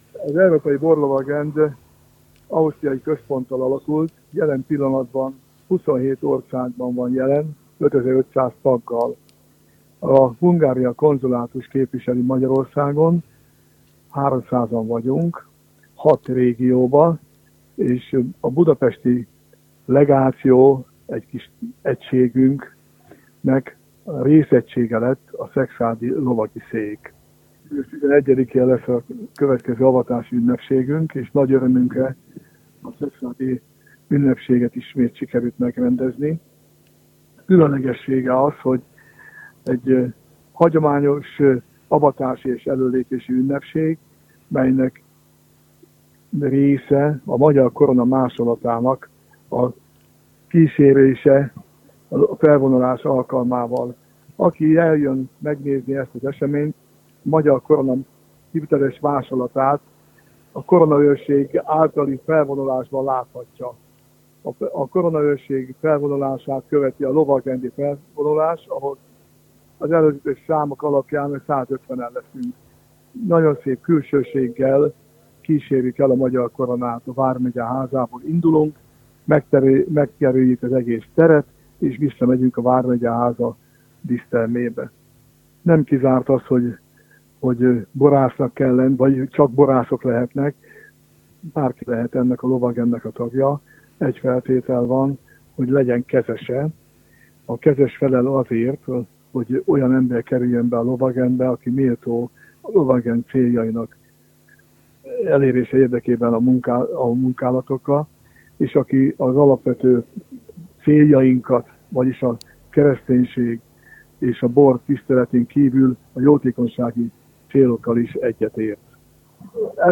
borlovagrend_riport_export.mp3